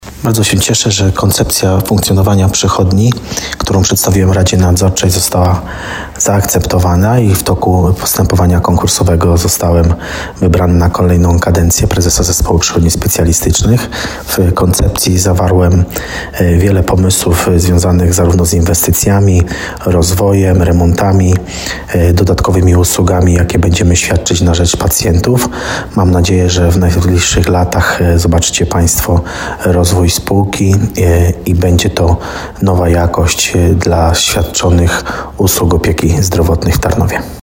Dobrze, że będę mógł je kontynuować i dokończyć to, co wspólnie z zespołem zaczęliśmy – powiedział w rozmowie z RDN Małopolska.